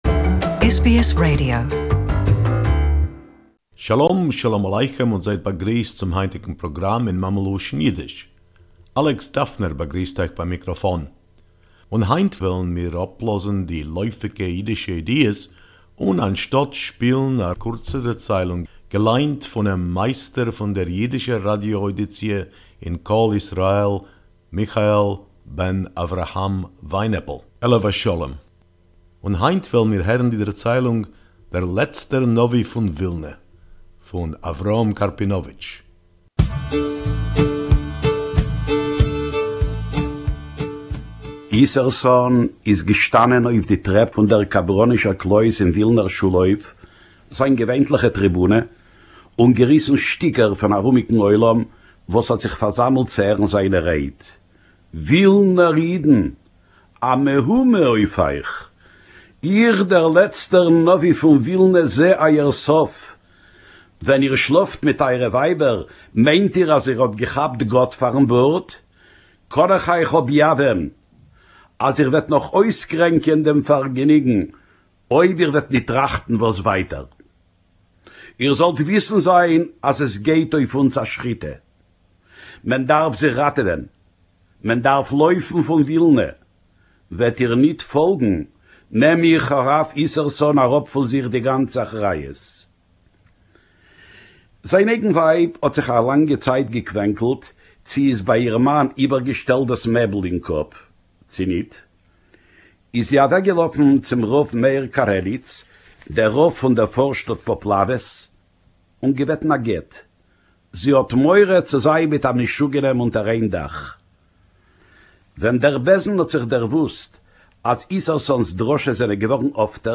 Yiddish Story